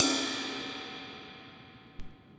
Dusty Cymbal 02.wav